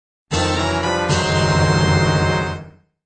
Прикольный Звук на СМС